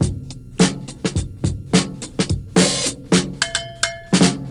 Free breakbeat sample - kick tuned to the C note. Loudest frequency: 2331Hz
86-bpm-breakbeat-sample-c-key-XVG.wav